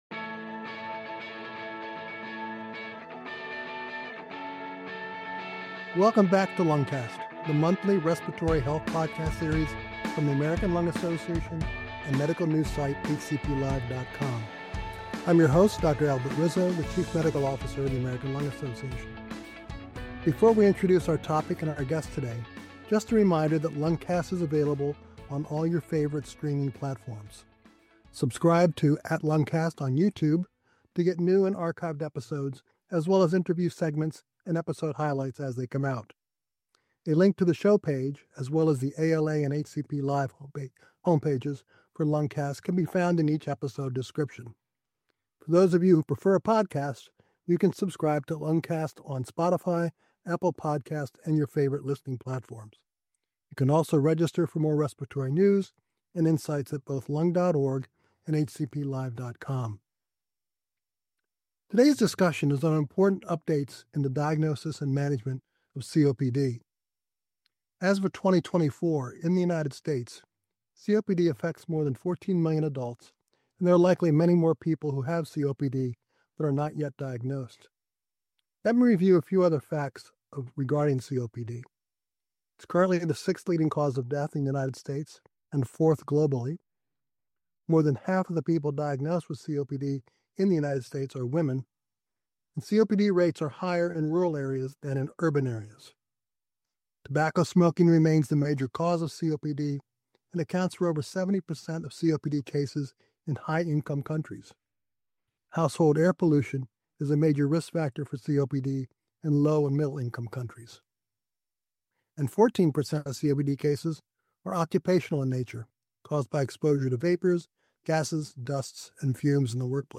interviews world-leading experts on the great challenges, accomplishments, and stories in respiratory medicine.